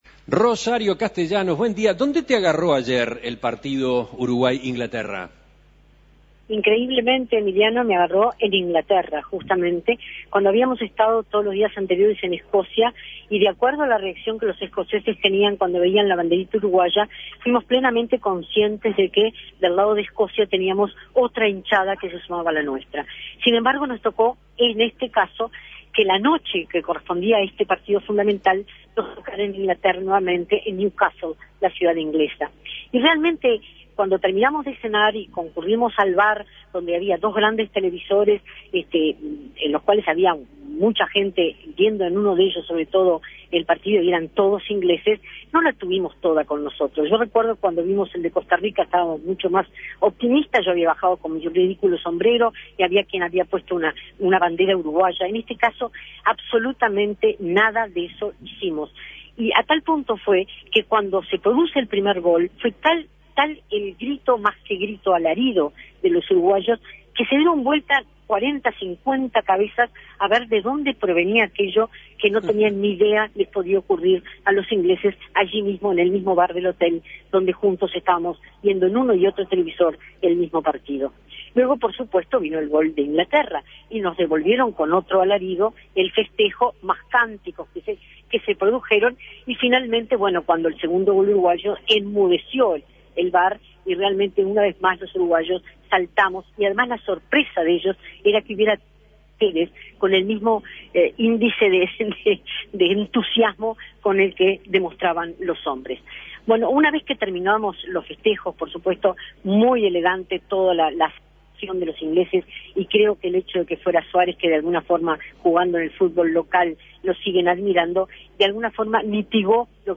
Comentario desde las Islas Británicas